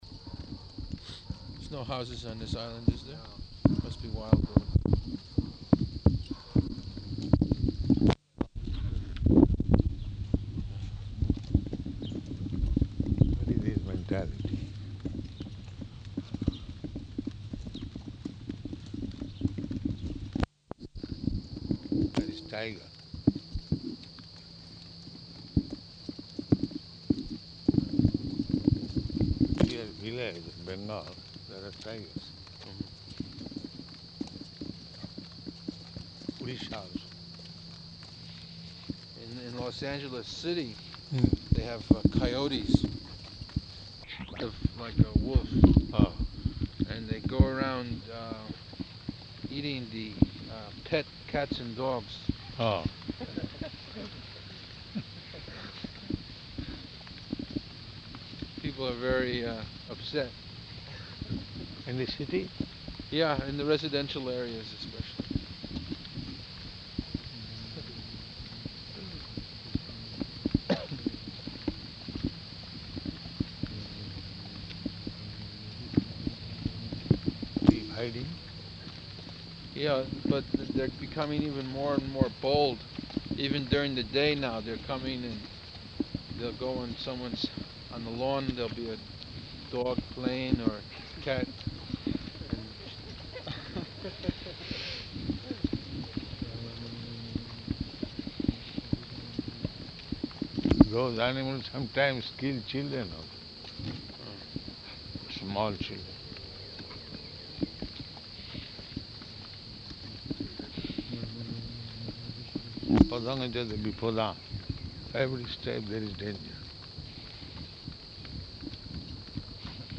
-- Type: Walk Dated: August 6th 1975 Location: Detroit Audio file